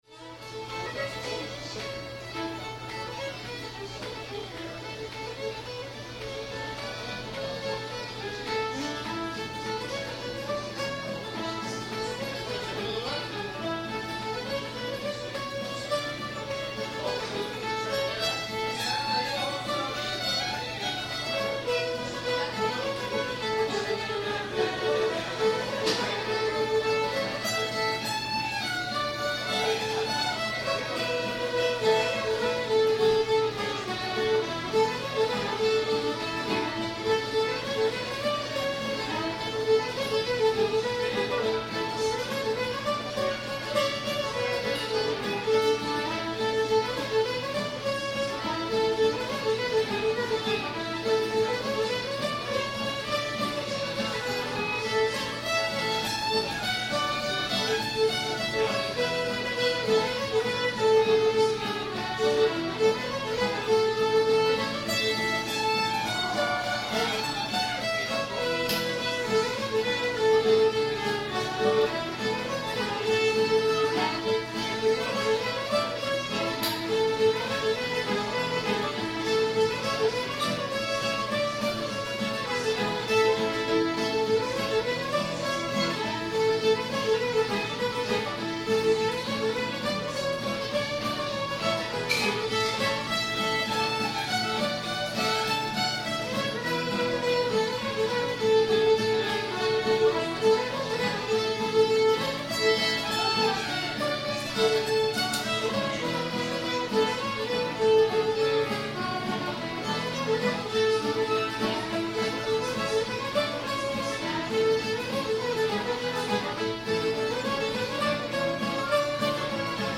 sweet nell [A]